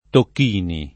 [ tokk & ni ]